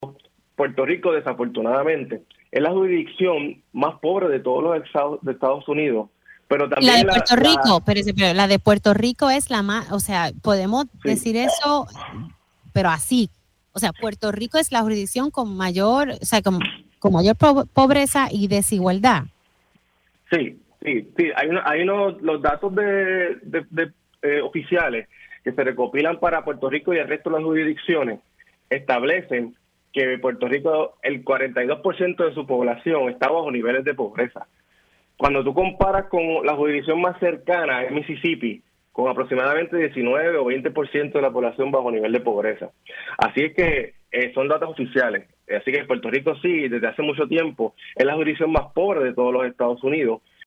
Puerto Rico es la jurisdicción más pobre de los Estados Unidos, así informó el director del Instituto de Estadísticas de Puerto Rico (IEPR), Orville Disidier en Pega’os en la Mañana.